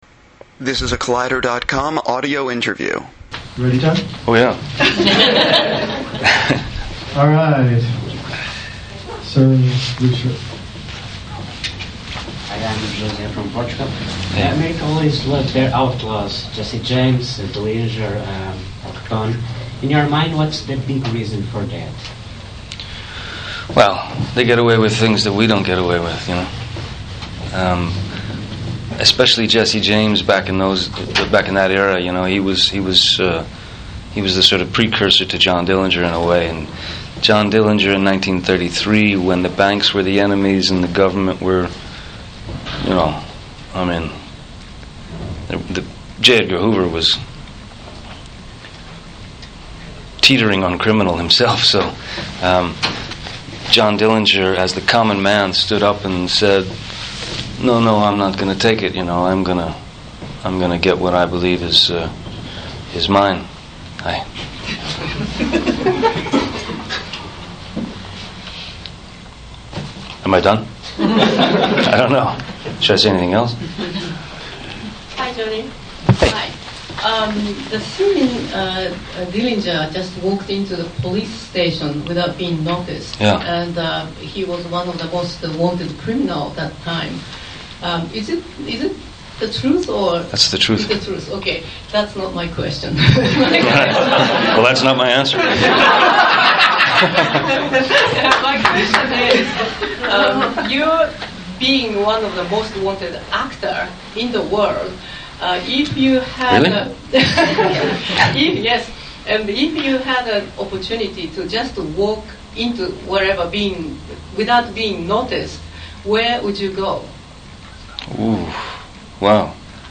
Public Enemies Radio Interview
Public Enemies: Audio press conference